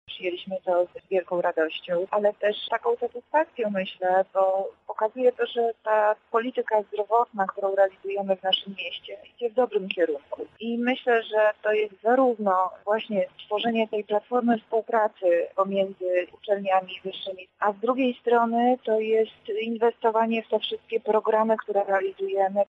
– mówi Monika Lipińska, zastępca prezydenta miasta